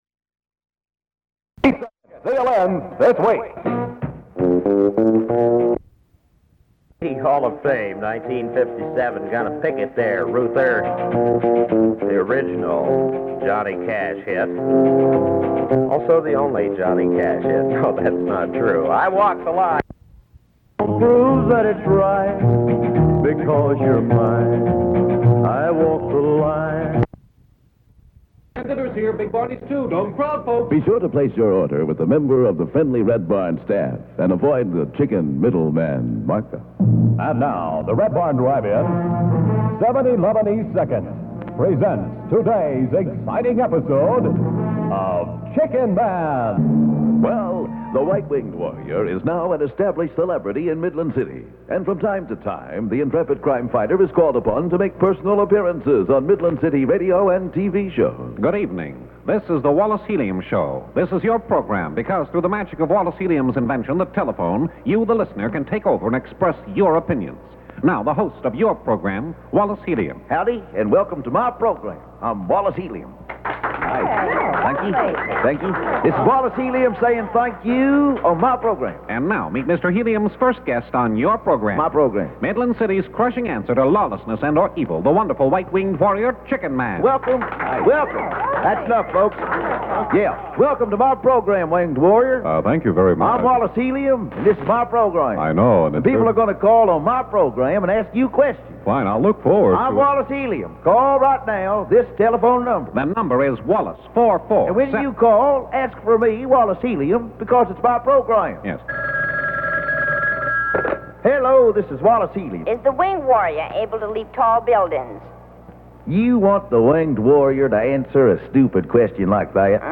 aircheck at beginning Chickenman Open later